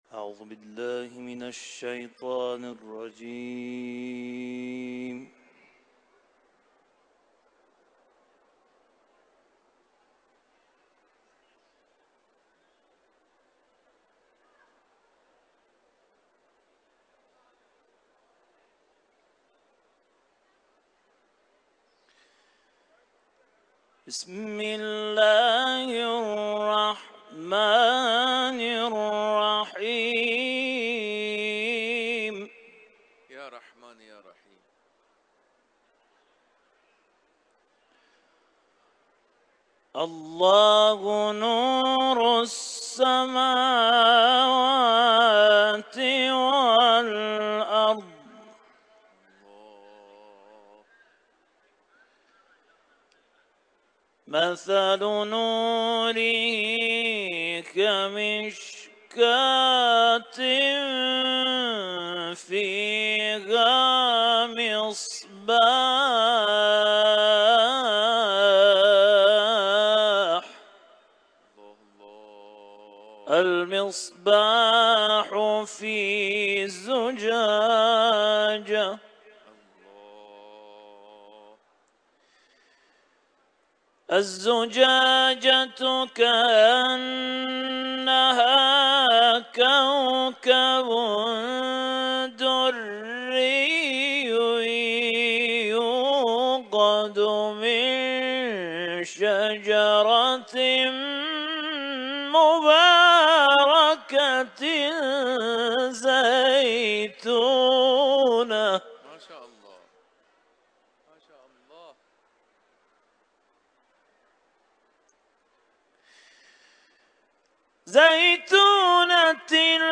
Etiketler: Nur suresi ، İmam rıza türbesi ، kuran ، tilavet